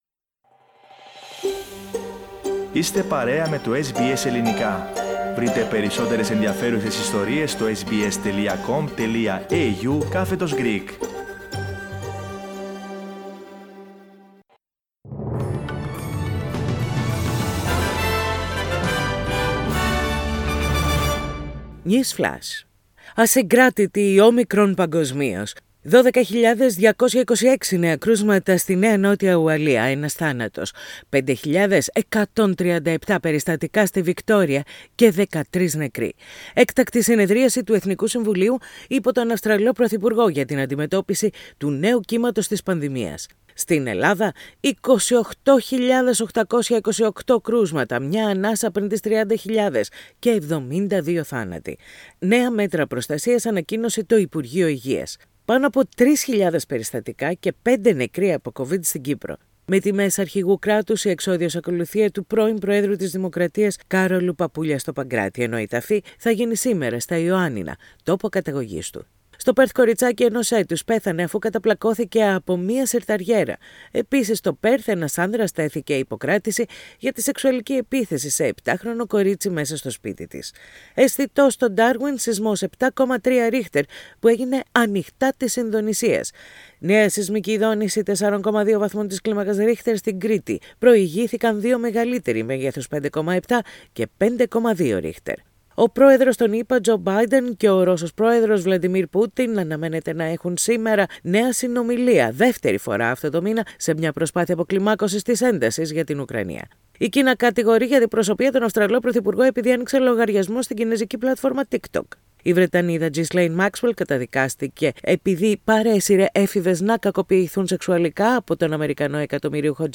News flash in Greek. Source: SBS Radio